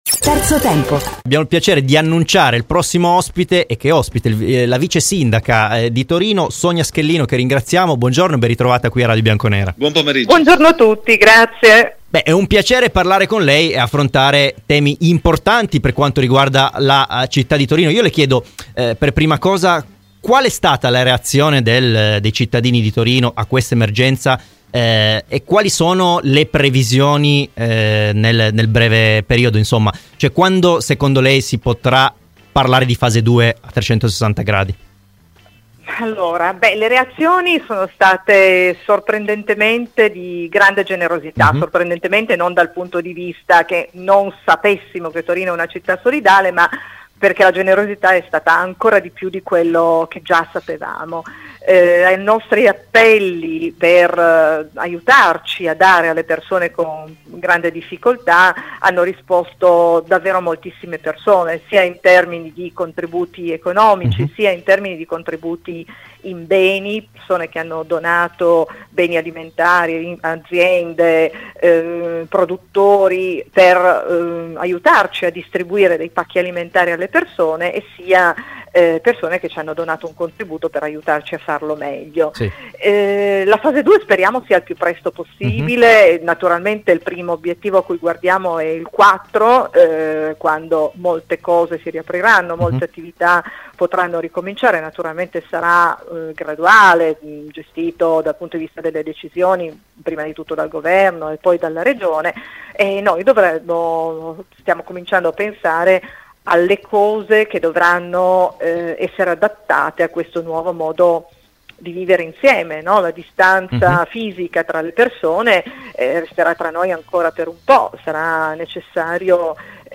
Sonia Schellino, vicesindaco di Torino, è intervenuta oggi ai microfoni di Radio Bianconera nel corso di ‘Terzo Tempo’.